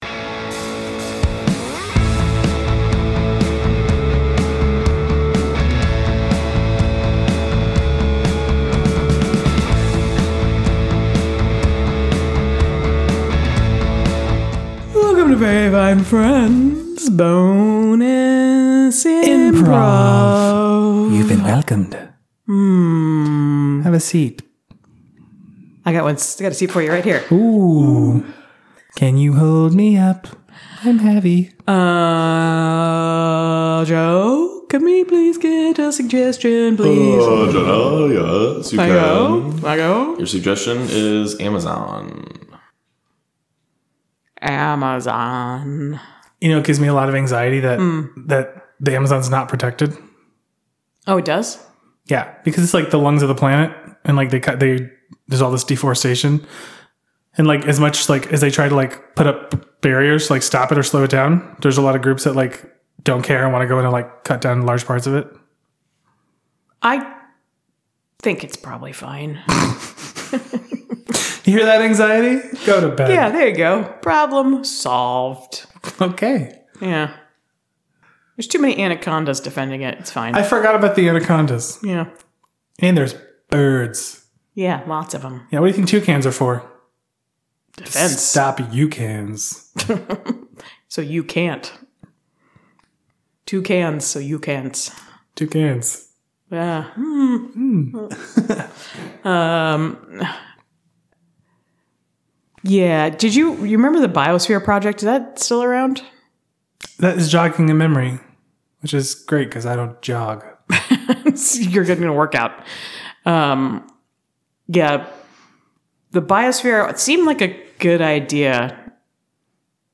Bonus IMPROV - This Is Not Like the Sand Back Home